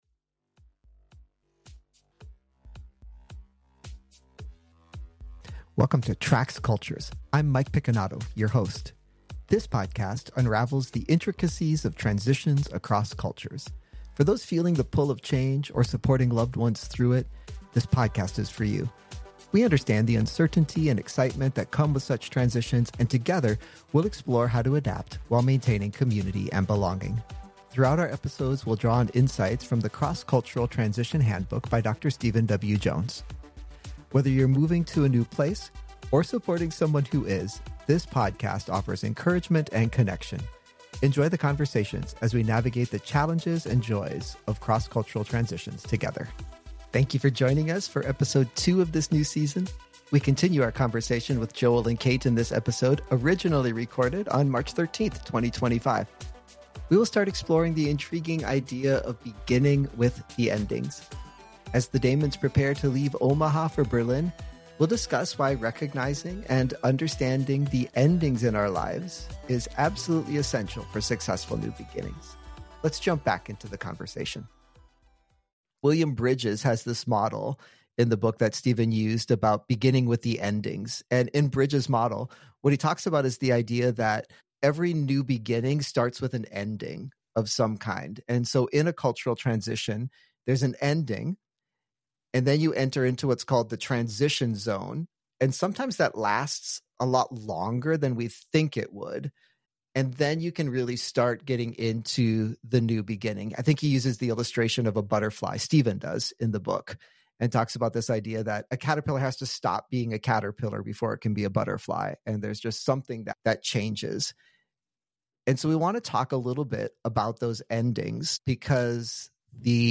Recorded originally on March 13, 2025, this conversation delves into the need to recognize and cope with endings as an essential part of new beginnings.